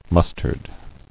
[mus·tard]